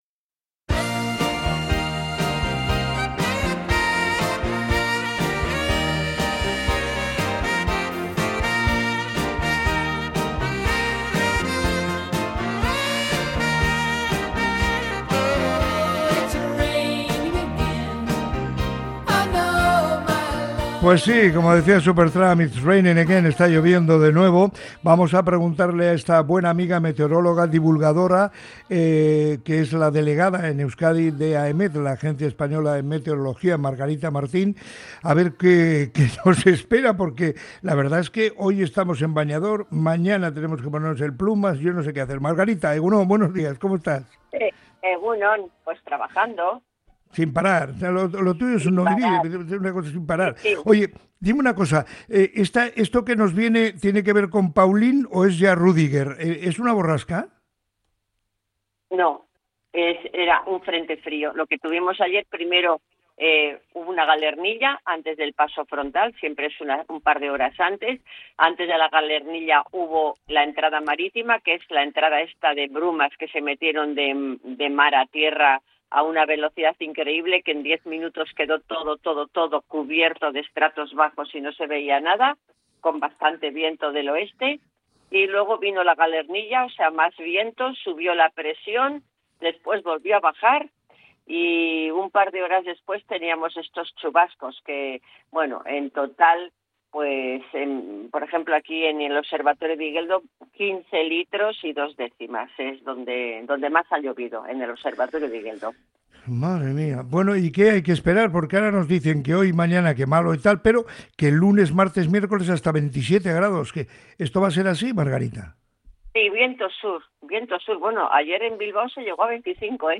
Podcast Sociedad